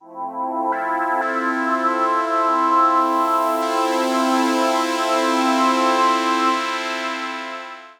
Mellow Pad A3.wav